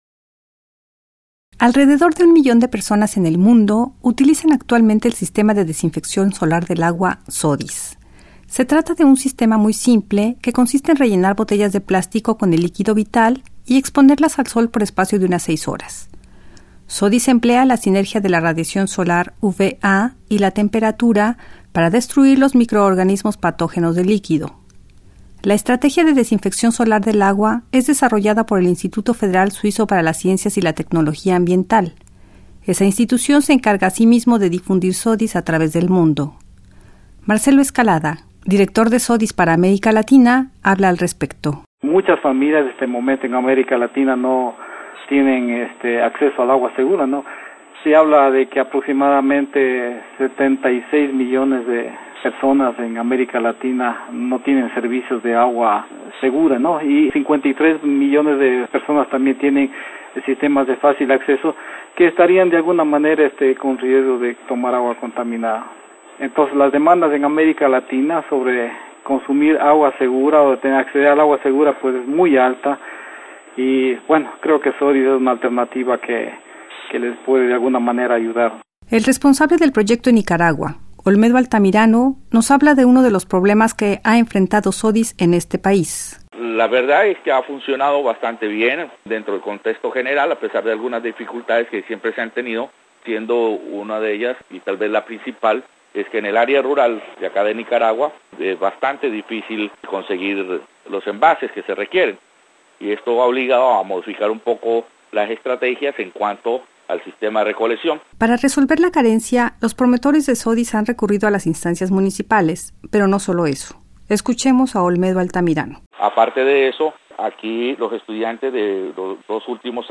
Alrededor de un millón de seres humanos utilizan ese sistema desarrollado por una institución suiza, para la purificación del líquido vital. Cada año dos millones de personas, en su mayoría niños, mueren víctimas de la diarrea, como consecuencia del consumo de agua contaminada. Reportaje